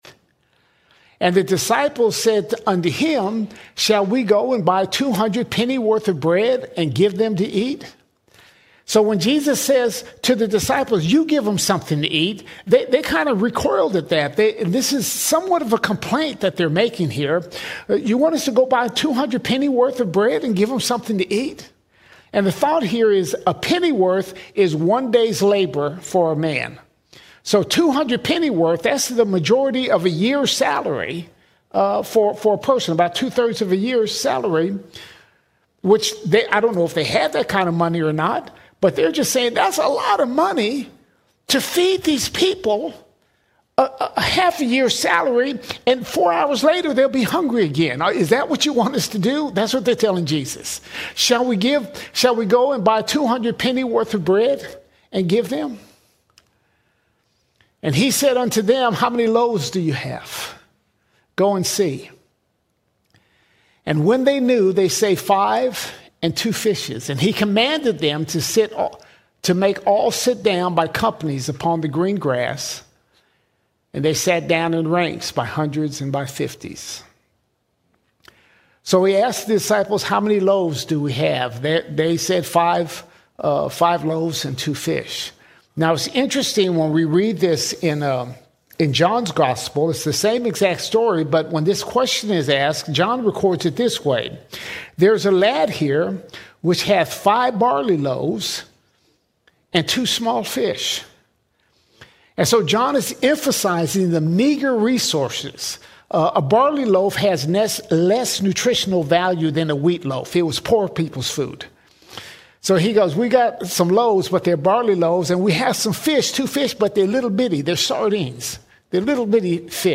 12 December 2025 Series: Gospel of Mark All Sermons Mark 6:31 - 7:13 Mark 6:31 – 7:13 Jesus calls us to rest in Him and challenges the empty traditions that distract our hearts.